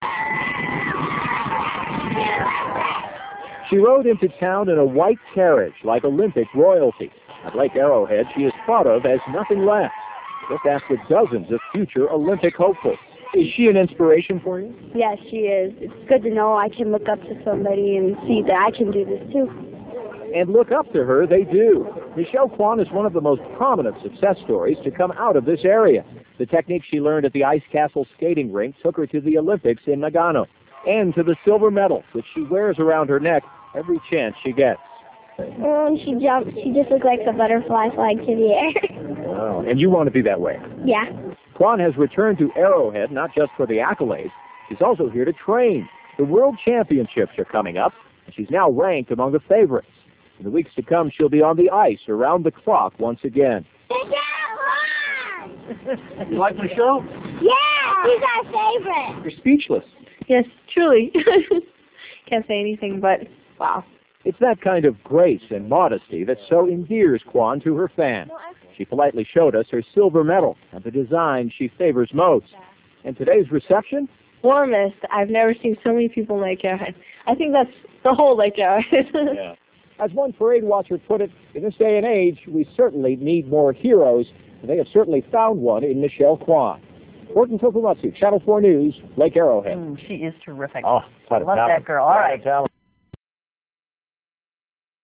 Interview from Michelle Kwan Day 3/7/98 KNBC AVI 1.6MB